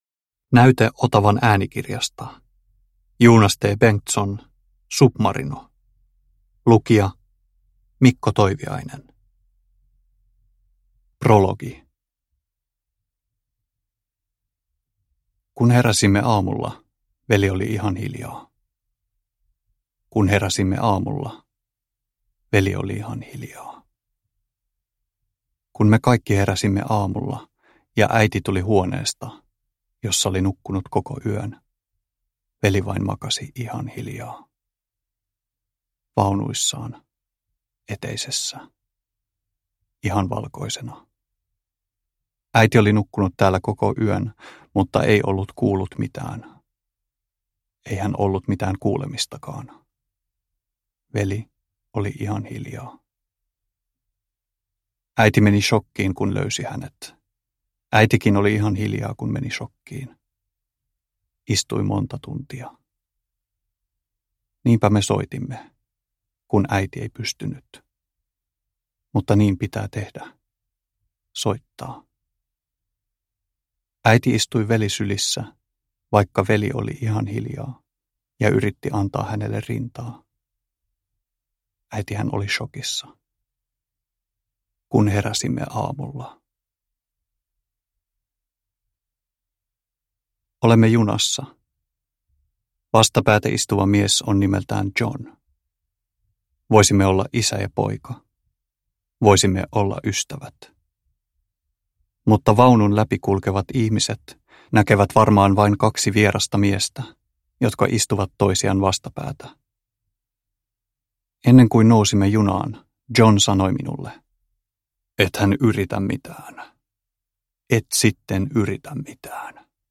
Submarino – Ljudbok – Laddas ner